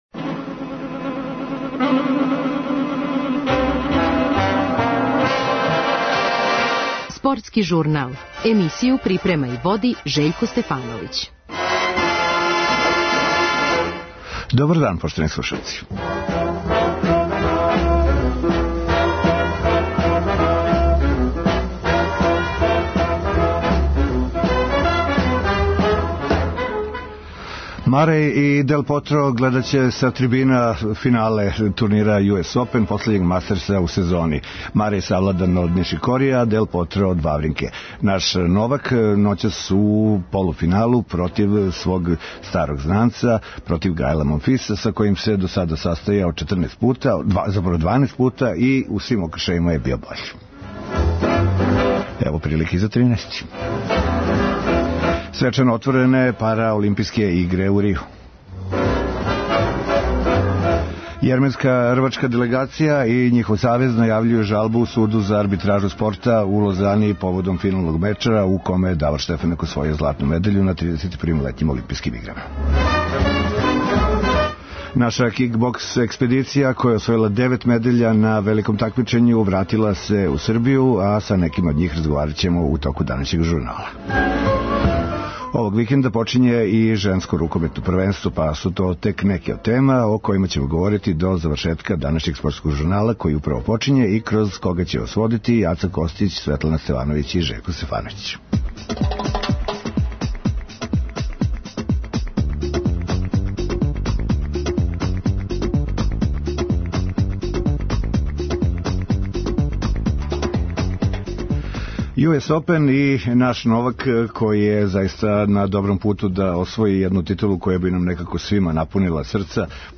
На јуниорском првенству света у кик боксу, одржаном у Даблину, наши представници освојили су чак девет медаља, чућемо утиске славодобитника и њиховог стручног вођства. У Рију су свечано отворене Параолимпијске игре, које трају до 17. септембра.